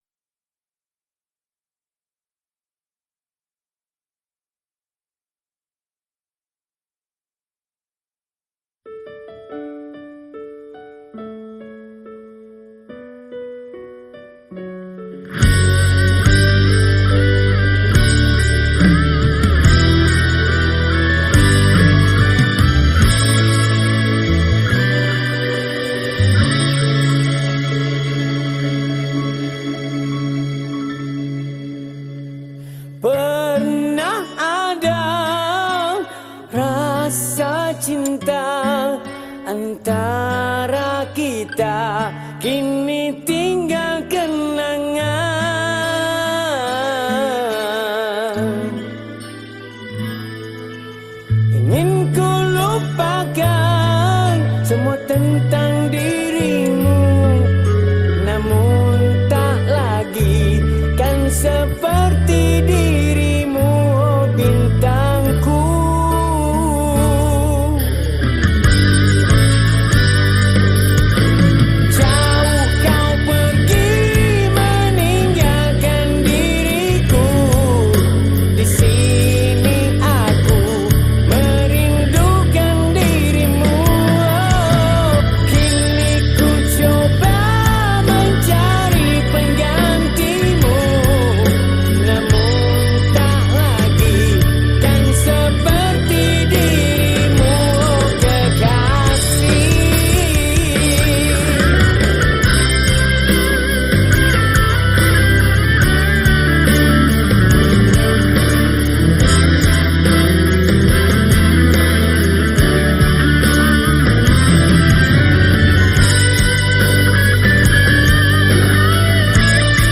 Malay Songs
Skor Angklung